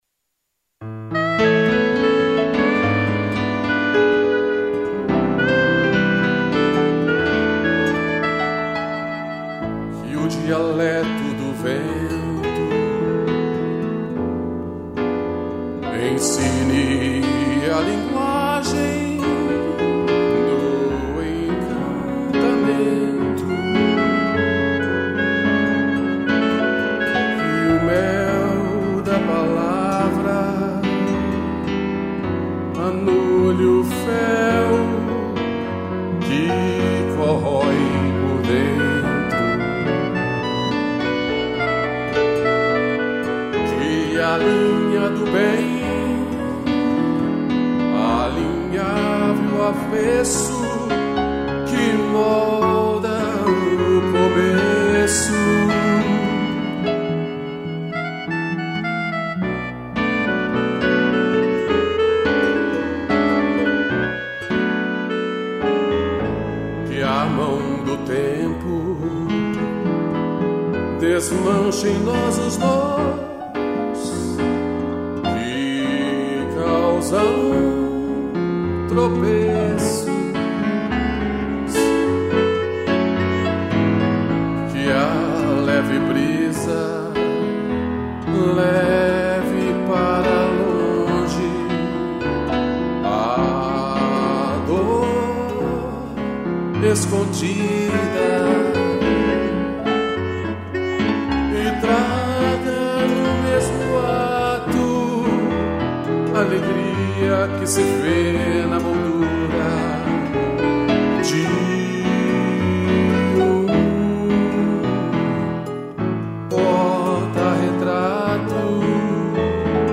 Voz
2 pianos e clarinete